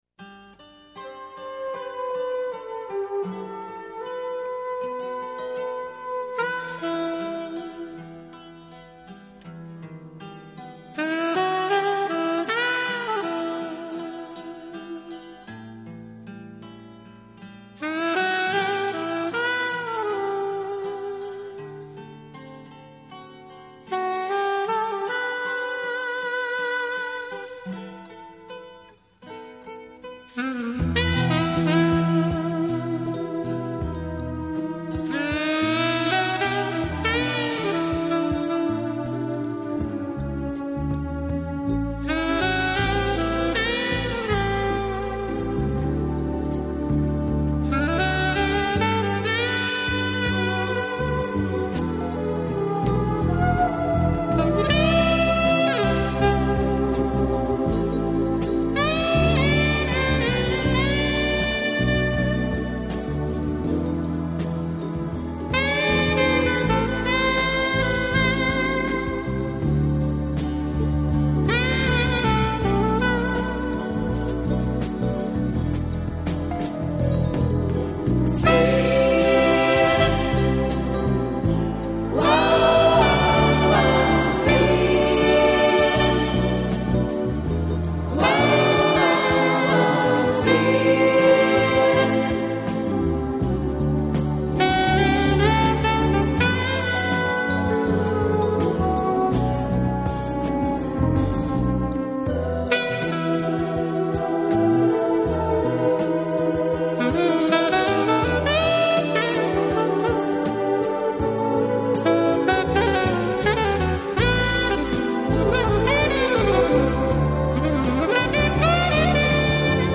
本片音色优美，配器丰富，合声部分饱满悠远，极富感染力。